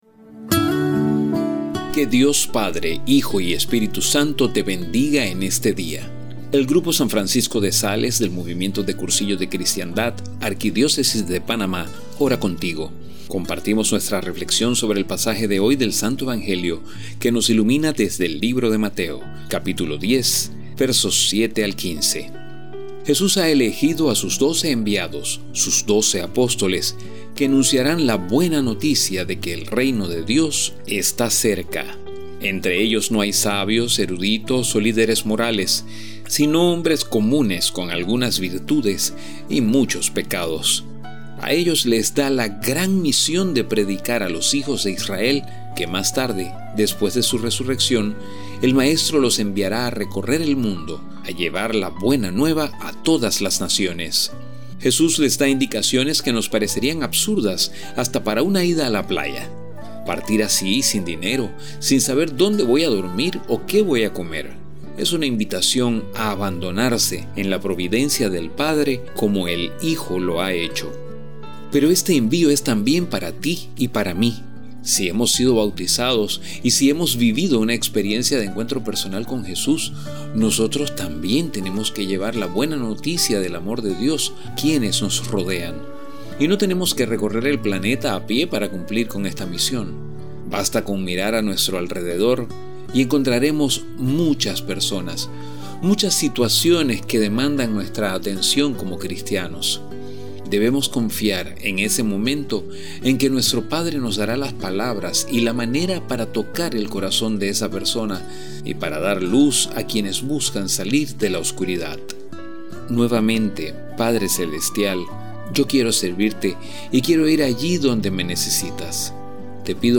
A  continuación la audioreflexión preparada por el grupo «San Francisco de Sales» del Movimiento de Cursillos de Cristiandad de la Arquidiócesis de Panamá, junto a una imagen para ayudarte en la contemplación.